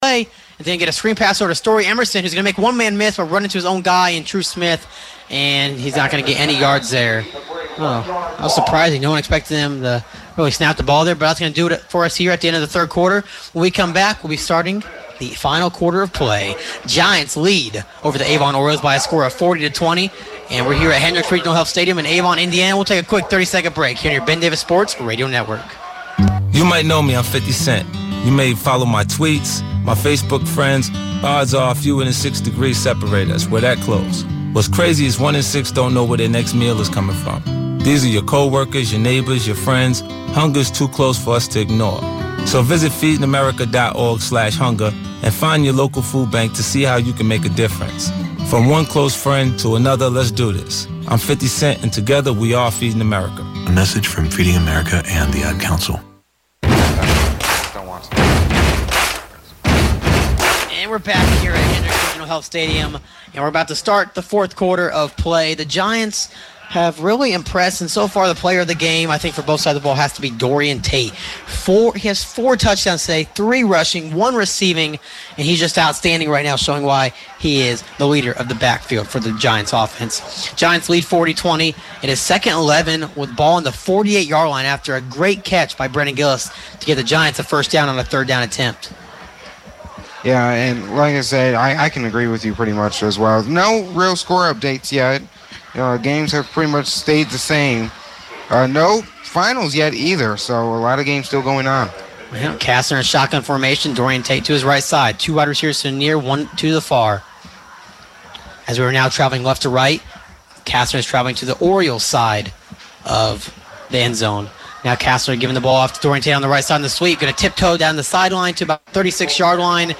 The 4th Quarter of action between Ben Davis and Avon High School's football teams as broadcast on WBDG.